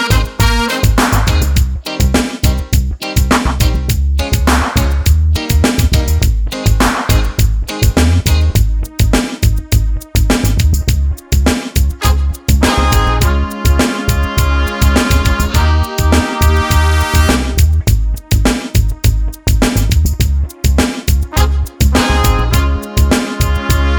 no Backing Vocals Reggae 3:31 Buy £1.50